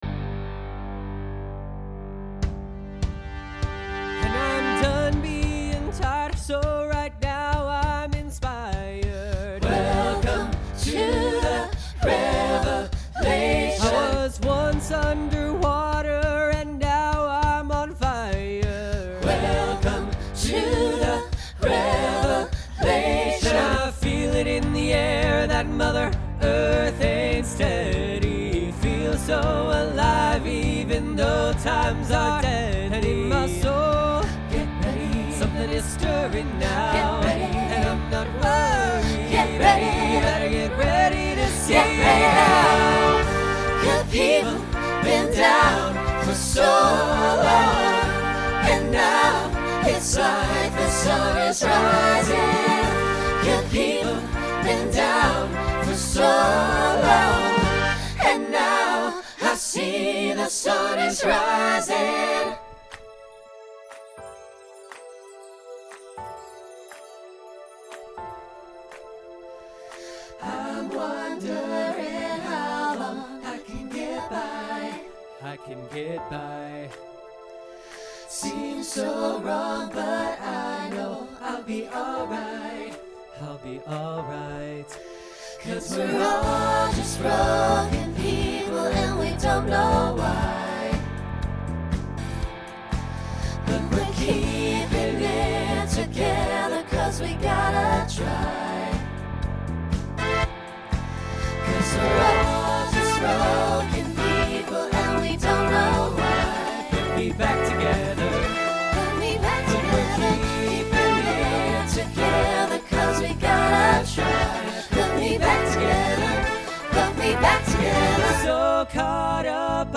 Pop/Dance , Rock
Voicing SATB